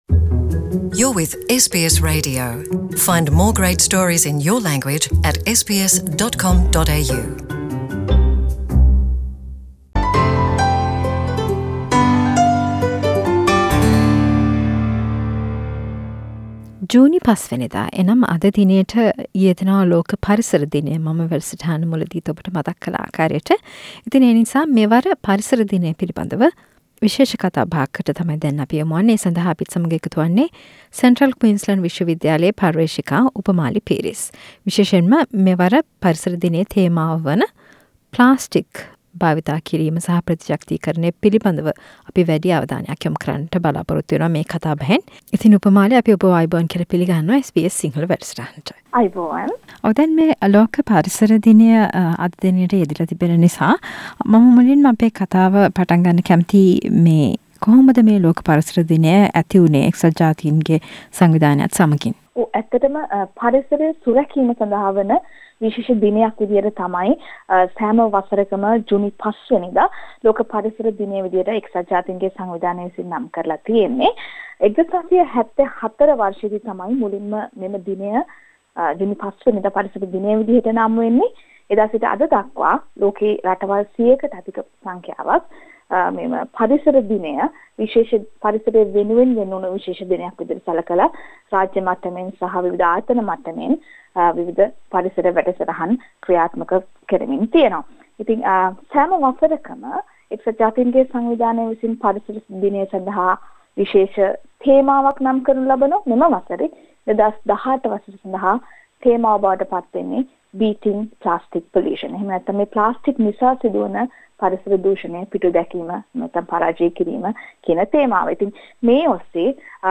A discussion to mark World Environment day